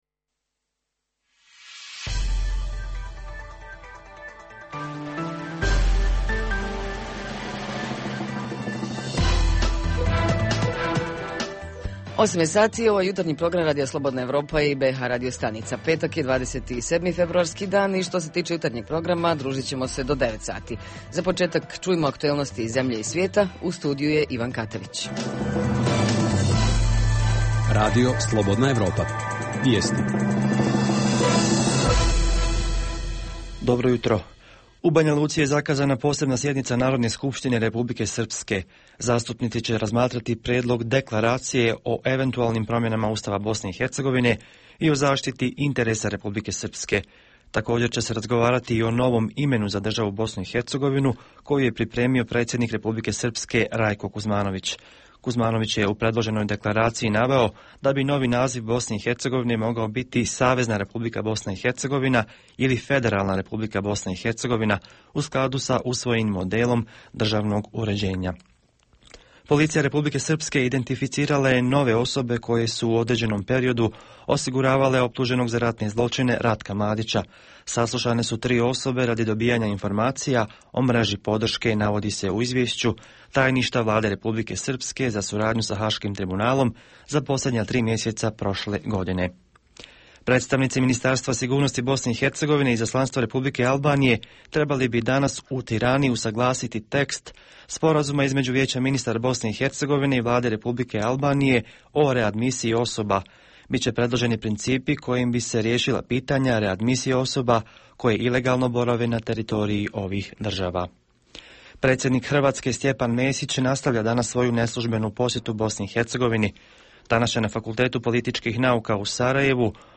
Jutarnji program za BiH koji se emituje uživo. Tema jutra: škole kao odgojno – obrazovne institucije.
Redovni sadržaji jutarnjeg programa za BiH su i vijesti i muzika.